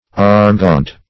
Search Result for " armgaunt" : The Collaborative International Dictionary of English v.0.48: Armgaunt \Arm"gaunt`\, a. With gaunt or slender legs.